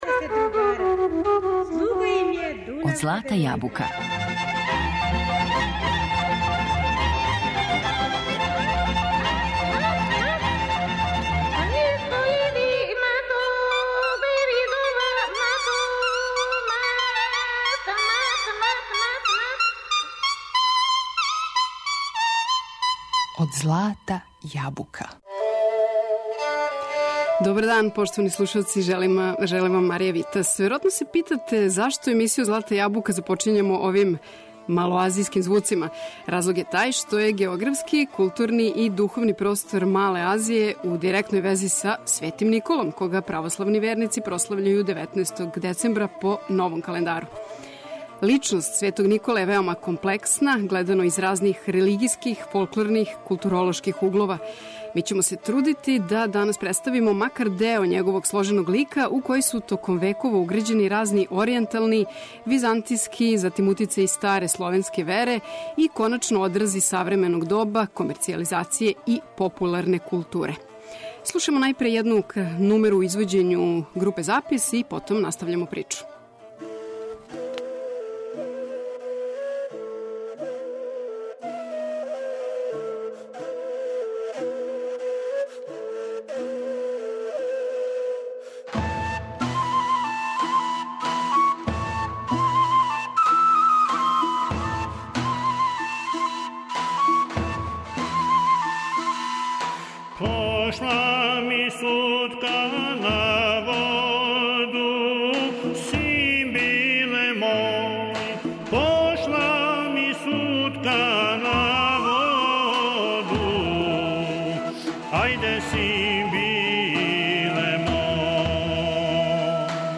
Како је Никола, епископ античког града Мире из 4. века, својим рођењем и деловањем везан за простор Мале Азије, током емисије ћемо, осим српске, слушати и примере из грчког и турског музичког наслеђа, уз нумере из македонског и руског фолклора, јер је и у овим земљама Свети Никола веома уважен.